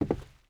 Footsteps_Wood_Walk_03.wav